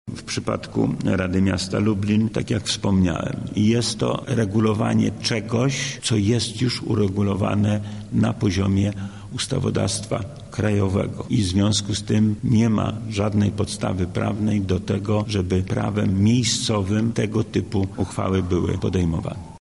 • mówi wojewoda lubelski Lech Sprawka.